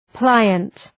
Προφορά
{‘plaıənt}